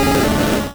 Cri de Reptincel dans Pokémon Rouge et Bleu.